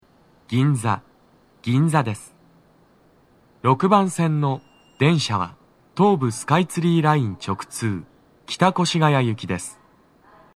男声
到着放送2